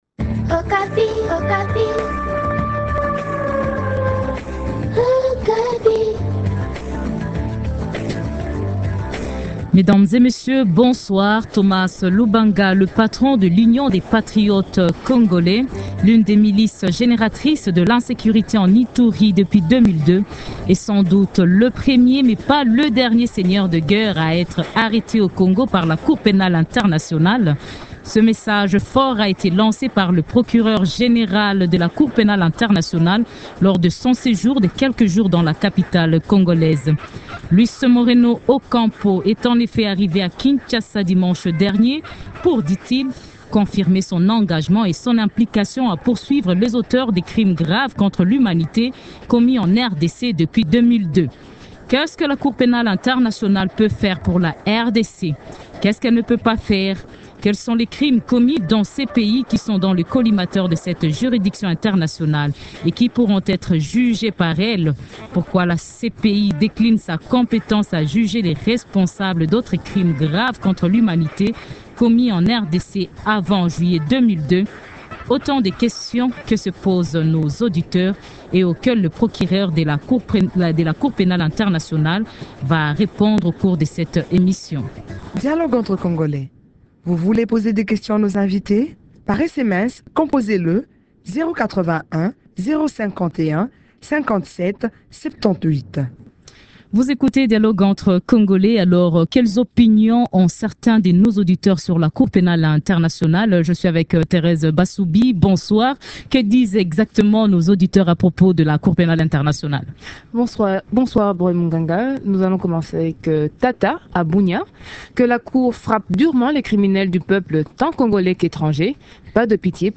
Dialogue entre congolais reçoit le Procureur général de la Cour Pénale Internationale